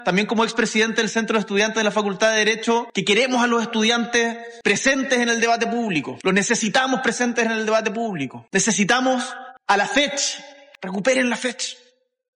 El mandatario asistió a la ceremonia de inauguración del megaproyecto arquitectónico “Cimientos de la Facultad”, una iniciativa que renueva desde sus bases el edificio patrimonial de Pío Nono 1.
Durante su alocución, y recordando su pasado como expresidente del Centro de Estudiantes de Derecho, el jefe de Estado dijo que quiere ver a los estudiantes “presentes en el debate público”, afirmando que son necesarios en la discusión del país.